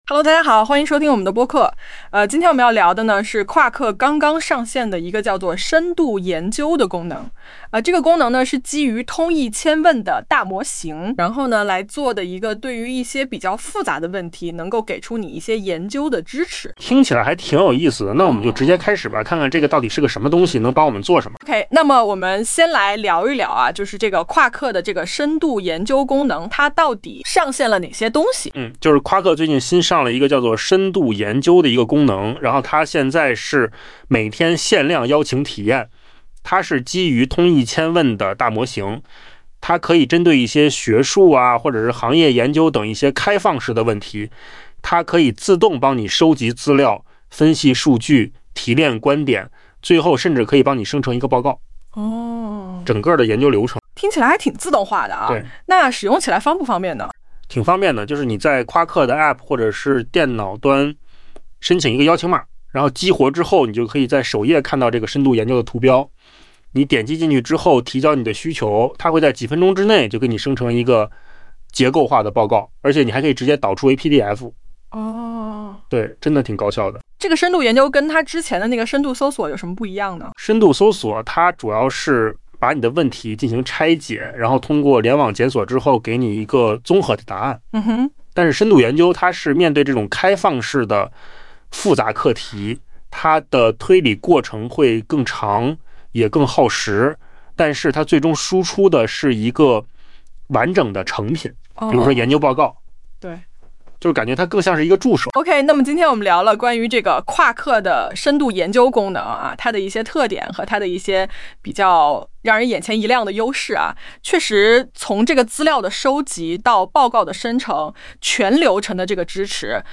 豆包交付的AI播客节目以男女双人对谈的形式进行，能对用户上传的网页、文件等进行深度改造，使其更适合听众消费。
在下方案例中，智东西将一篇数百字的快讯发送给了豆包，由于原本的信息量不大，最终其生成结果也略显单薄。大量语气词、口语化表述和模式化问答的存在，导致信息密度有待提高